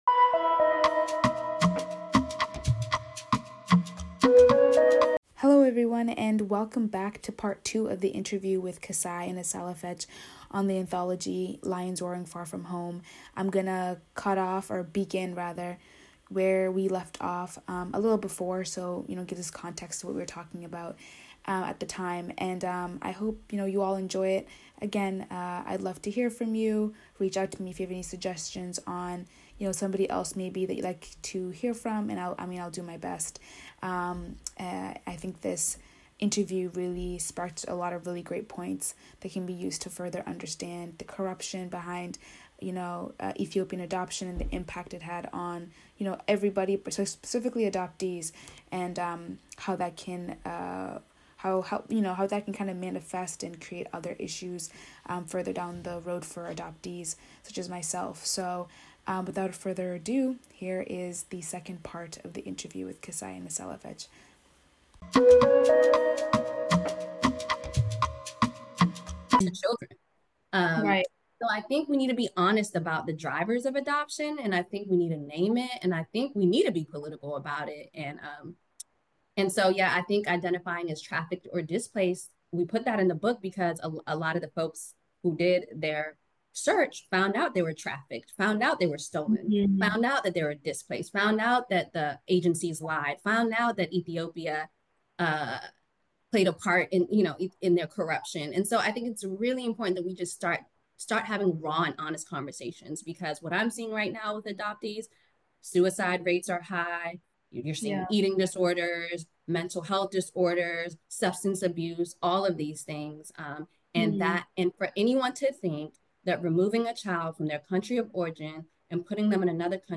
The conversation is organic, honest, and adoptee-centered.